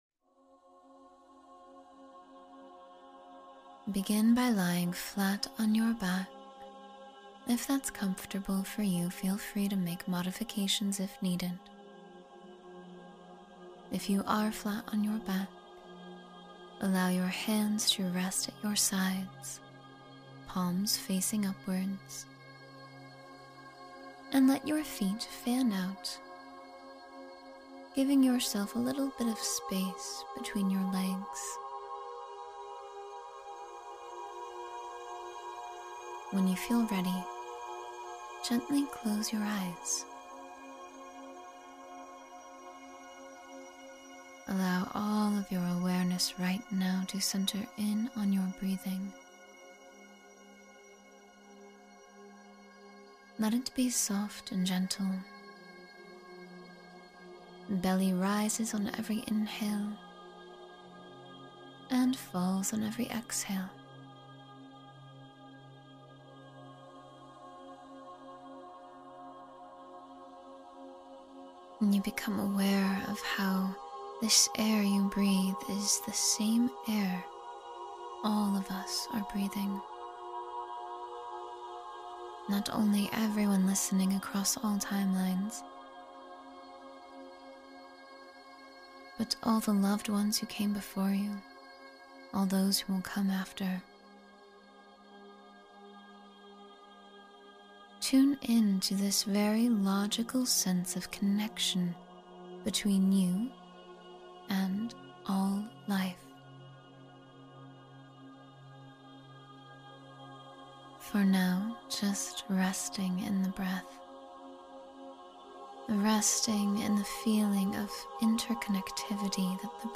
Feel Unity with All That Exists — Guided Meditation for Connection